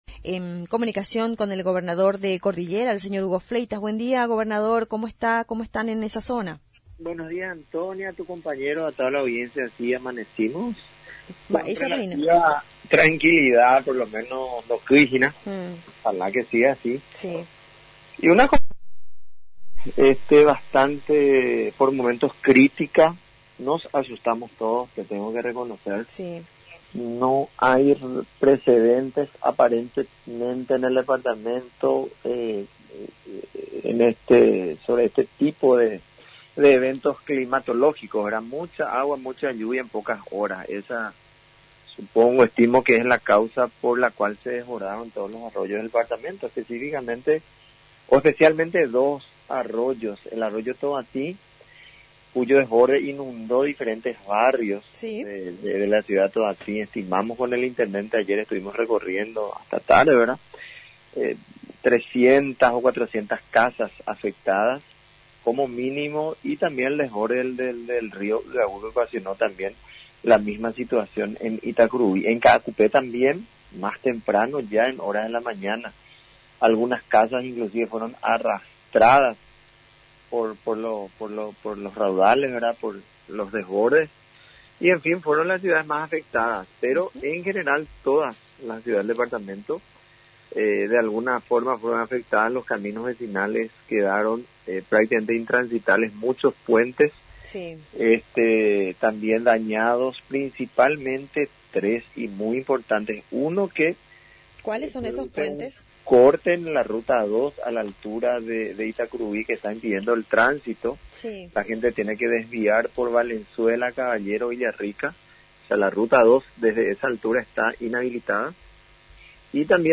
En una entrevista con nuestra emisora el Gobernador de Cordillera, Hugo Fleitas, evalúo el evento climatológico registrado en el Departamento y dijo que es sin precedente, mucha lluvias en pocas horas, por lo que se desbordaron los arroyos especialmente el Arroyo Tobati, de la Ciudad con el mismo nombre, el cual inundó diferentes barrios, estimando unas 300 a 400 familias, como también el desborde del Rio Yhaguy, misma condiciones, otro en Itacurubi y Caacupé, en donde algunas casas fueron arrastradas.